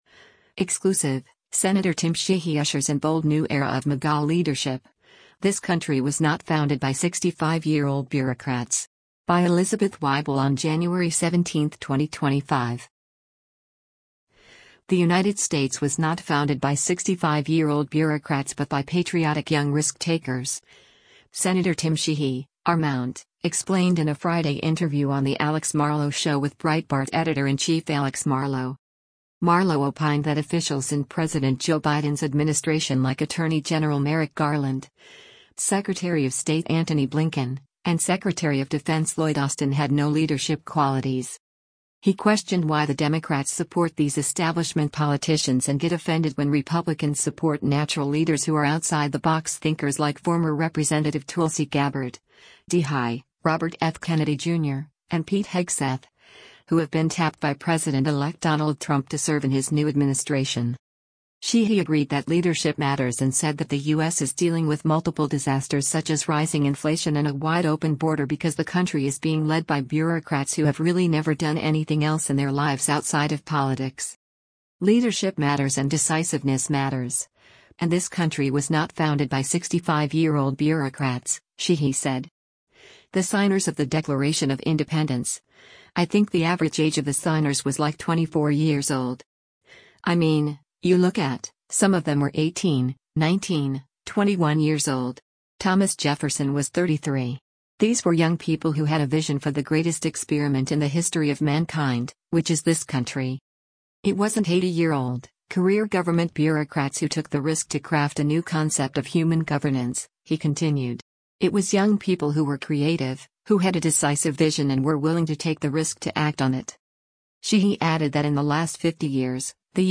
The United States “was not founded by 65-year-old bureaucrats” but by patriotic young risk takers, Sen. Tim Sheehy (R-MT) explained in a Friday interview on The Alex Marlow Show with Breitbart Editor-in-Chief Alex Marlow.
The Alex Marlow Show, hosted by Breitbart Editor-in-Chief Alex Marlow, is a weekday podcast produced by Breitbart News and Salem Podcast Network.